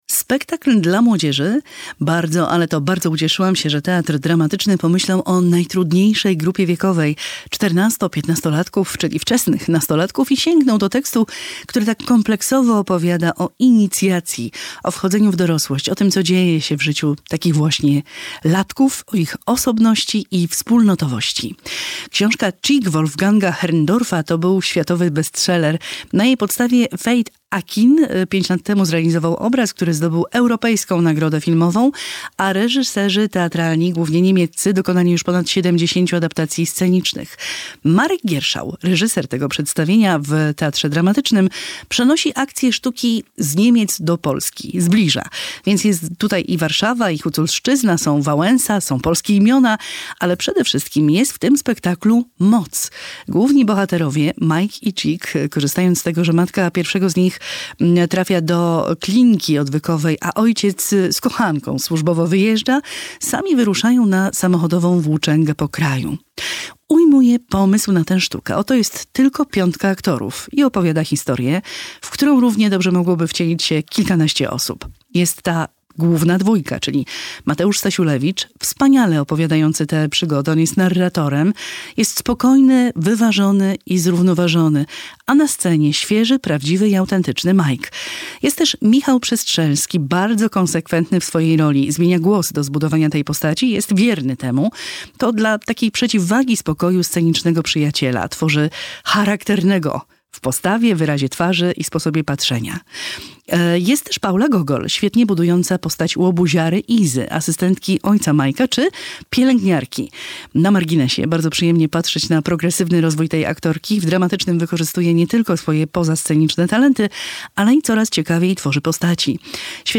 "Tschick" - spektakl drogi w Teatrze Dramatycznym im. Aleksandra Węgierki - recenzja